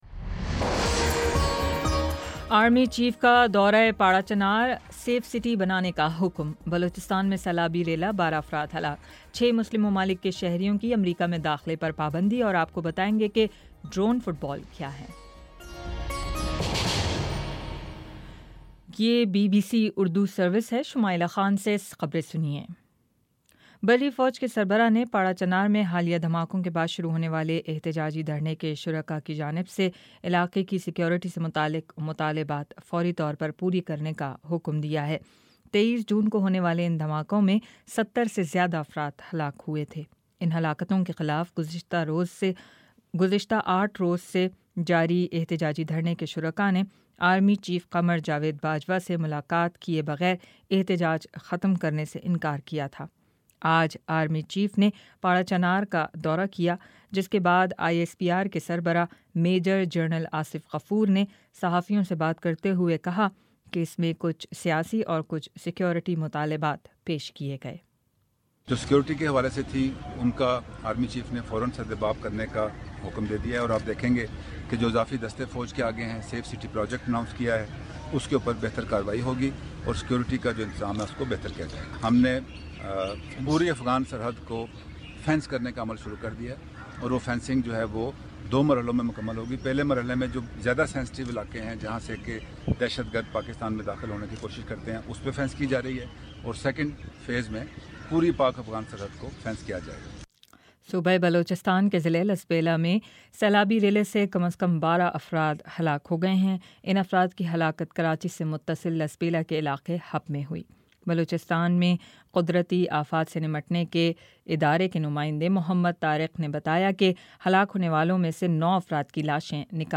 جون 30 : شام چھ بجے کا نیوز بُلیٹن